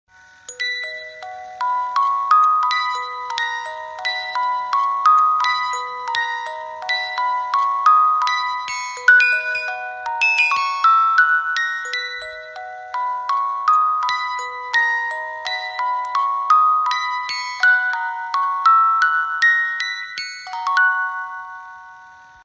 Romántico , Soundtrack